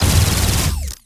Added more sound effects.
GUNAuto_Plasmid Machinegun C Burst Unstable_04_SFRMS_SCIWPNS.wav